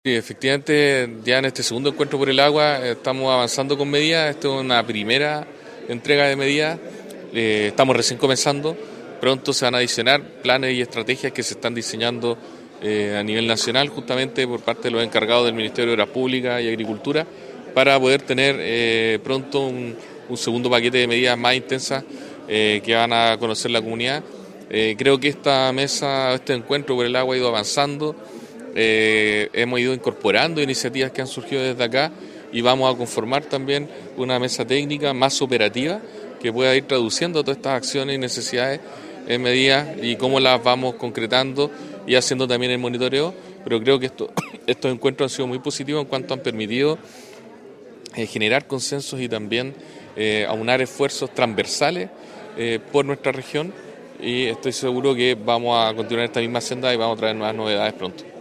Fue el segundo encuentro regional de coordinación de estrategias para enfrentar la crisis hídrica reunió a parlamentarios, autoridades de Gobierno, consejeros regionales, dirigentes sociales, empresarios, agricultores y crianceros que plantearon sus problemáticas para ir levantando medidas para hacer frente a esta crisis.
2-ENCUENTRO-AGUA-Delegado-Presidencial-Galo-Luna.mp3